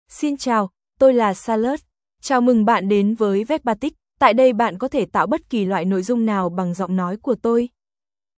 Charlotte — Female Vietnamese (Vietnam) AI Voice | TTS, Voice Cloning & Video | Verbatik AI
CharlotteFemale Vietnamese AI voice
Listen to Charlotte's female Vietnamese voice.
Female
Charlotte delivers clear pronunciation with authentic Vietnam Vietnamese intonation, making your content sound professionally produced.